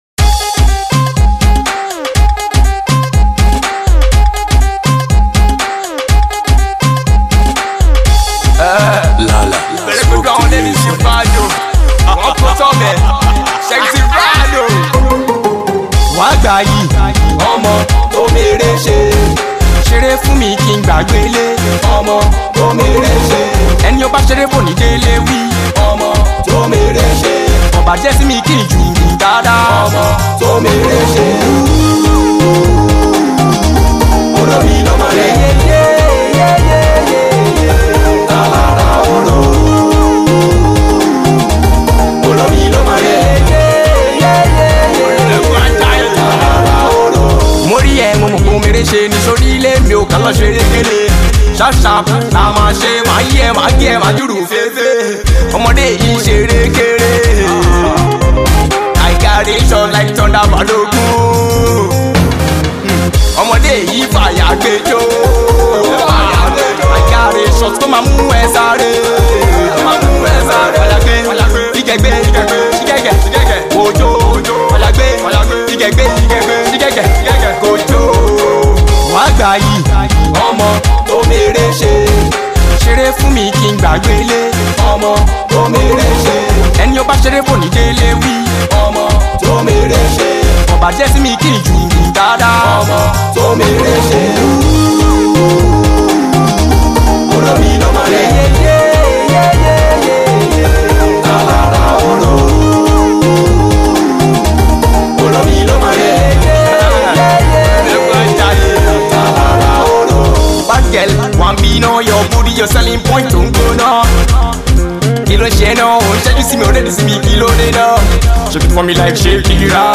a celebratory indigenous Pop record